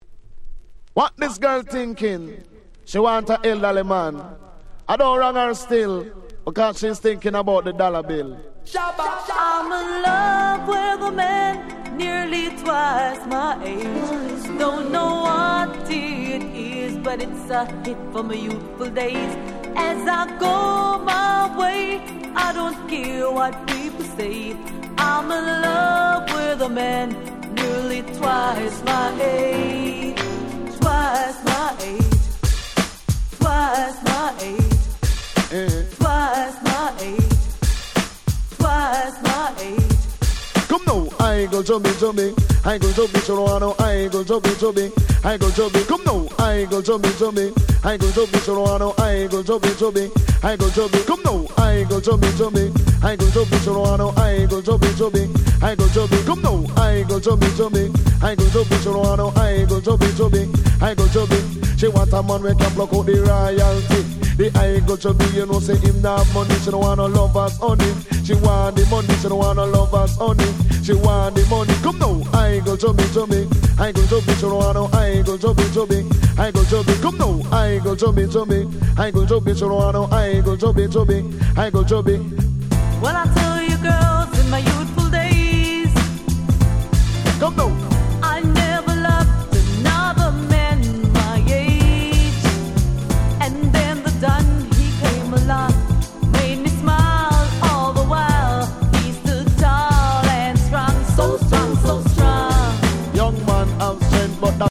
93' Very Nice Reggae / R&B Album !!
Dancehall